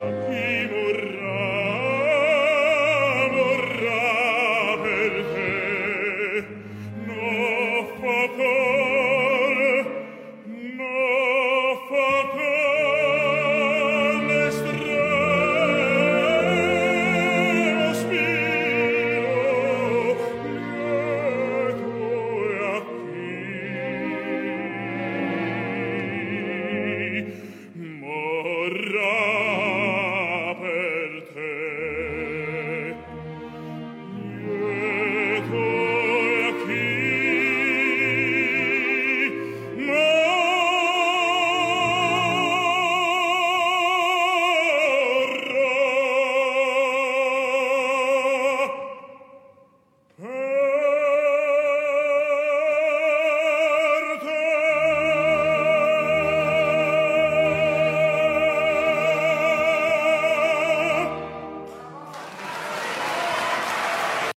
noble, radiant baritone of high standards with a singular, recognizable timbre